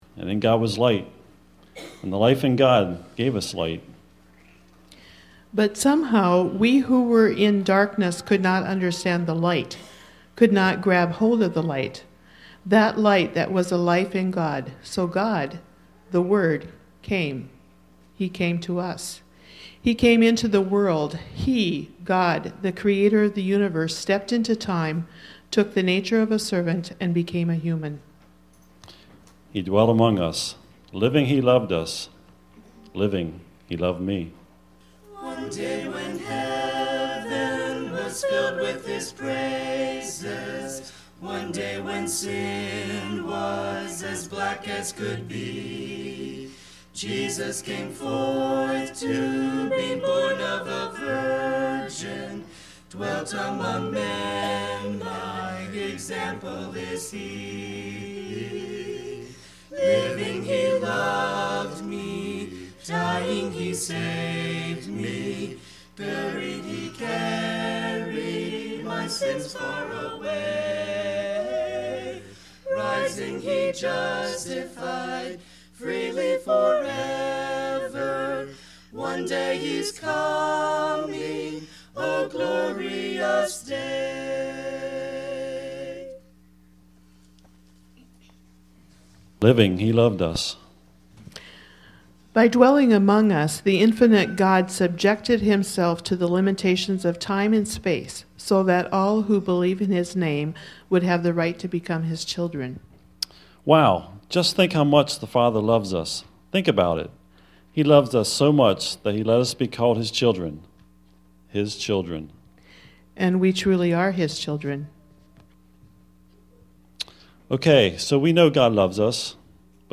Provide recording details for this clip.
Easter Service 2010